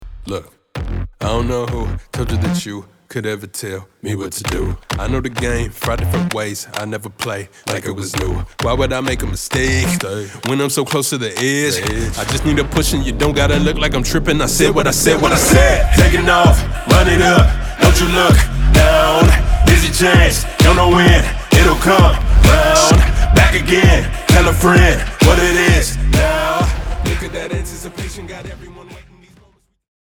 Rap